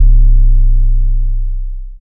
archived music/fl studio/drumkits/slayerx drumkit/808s